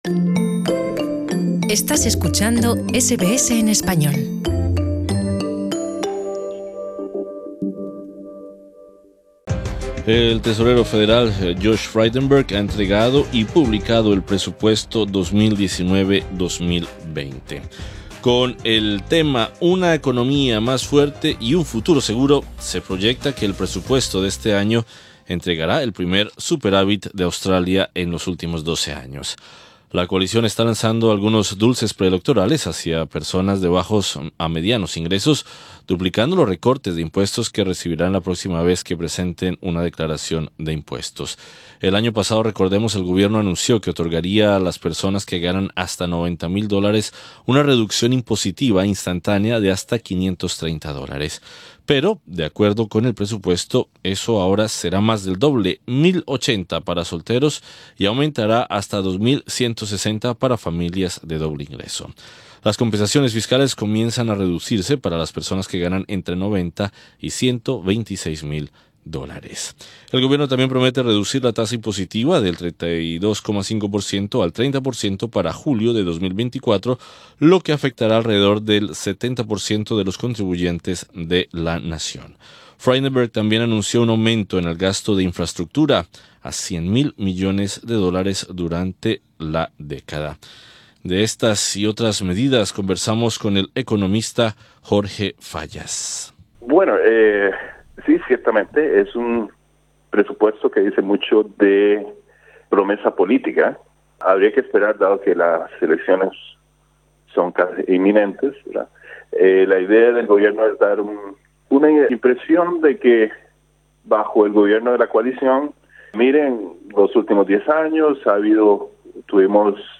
conversamos con el economista